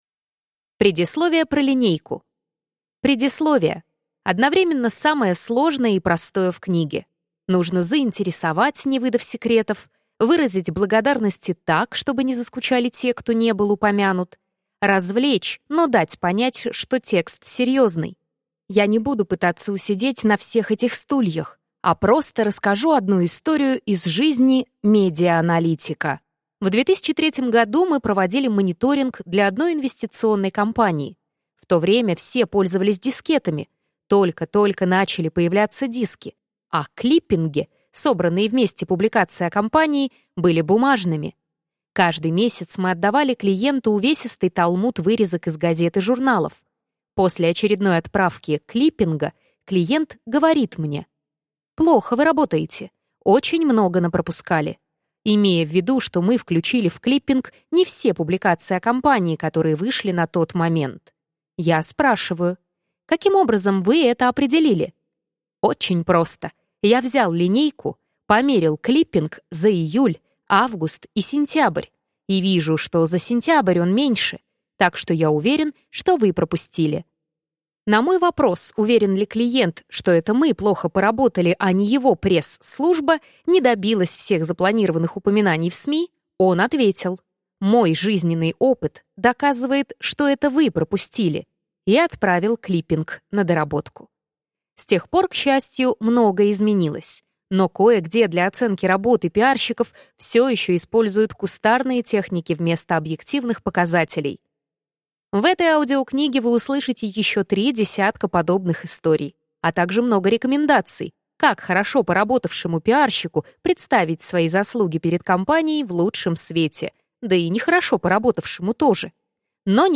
Аудиокнига Зачем пиарщику линейка? Советы по измерению коммуникаций | Библиотека аудиокниг